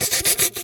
snake_2_hiss_06.wav